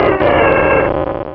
Cri de Galifeu dans Pokémon Rubis et Saphir.